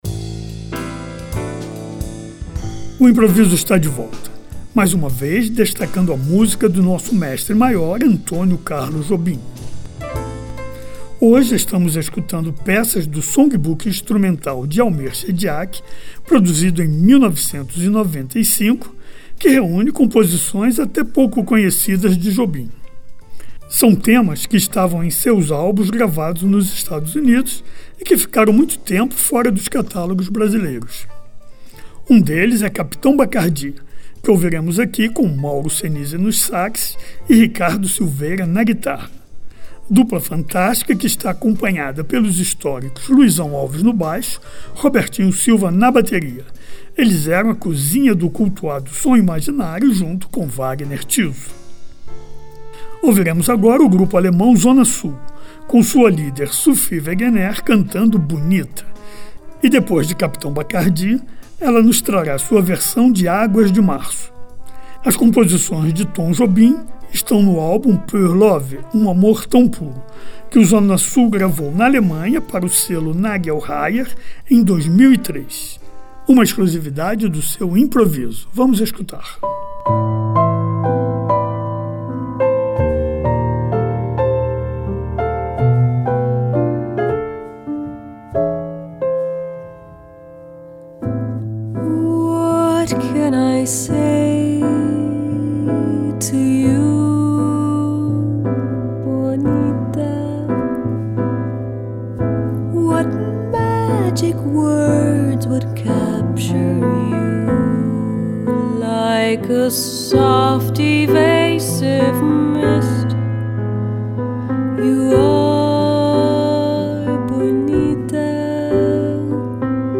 em versões instrumentais
É o inusitado grupo de jazz brasileiro
no piano
no baixo
na bateria
no violão.